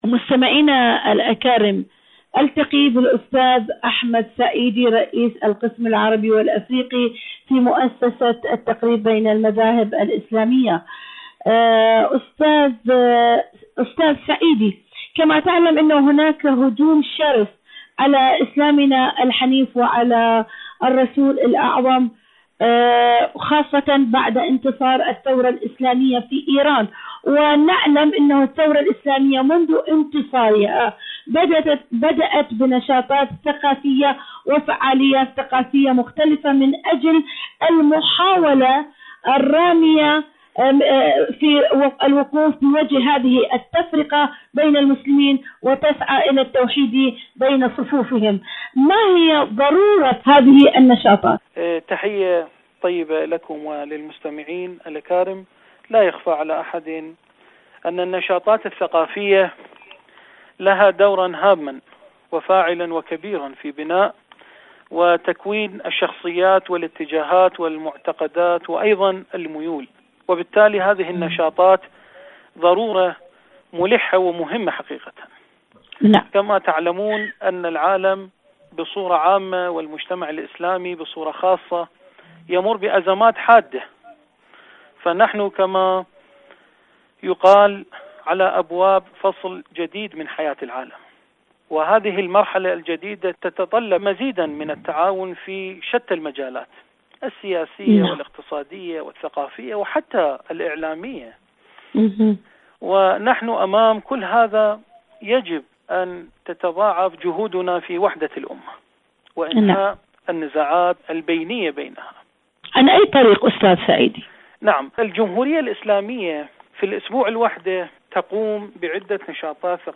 إذاعة طهران-ألوان ثقافية: مقابلة إذاعية